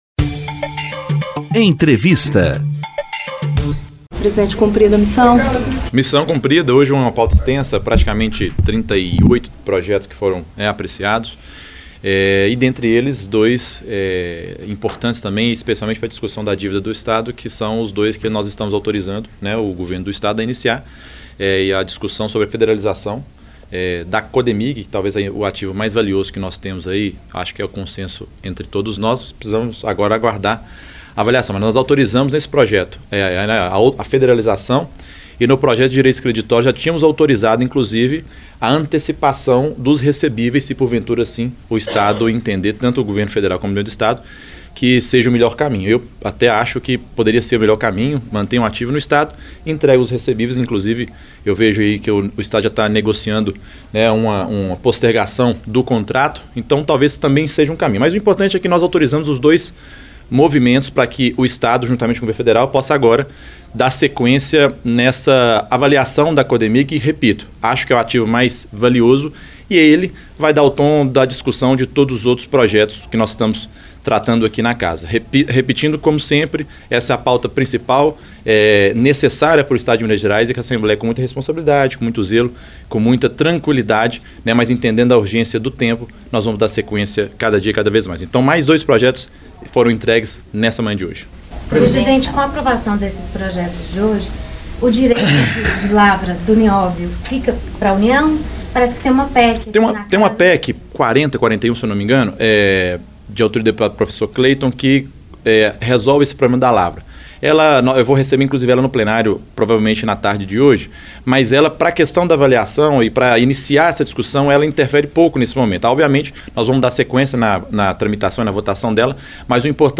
Entrevistas
Na entrevista coletiva, o presidente da Assembleia Legislativa também fala sobre os próximos passos para Minas aderir ao Propag, o Programa de Pleno Pagamento de Dívidas dos Estados.